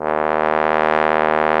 Index of /90_sSampleCDs/AKAI S-Series CD-ROM Sound Library VOL-2/1095 TROMBON